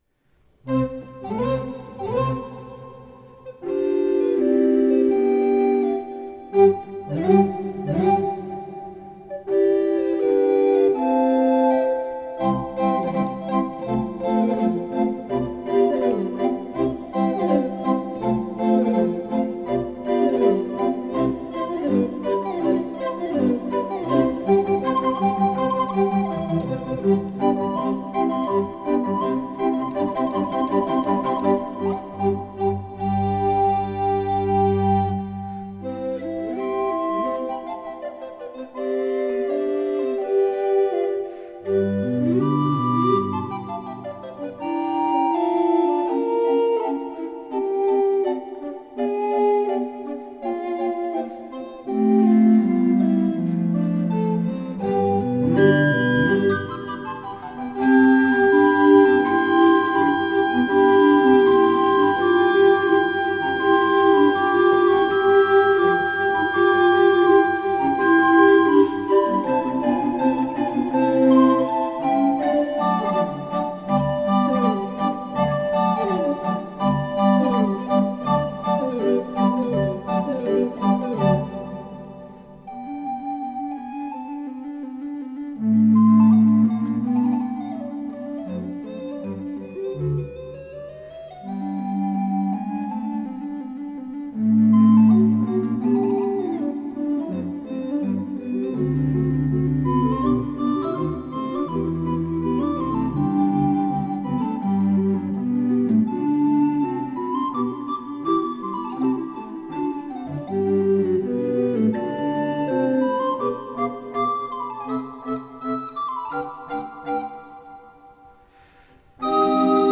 Orchestra  (View more Advanced Orchestra Music)
Classical (View more Classical Orchestra Music)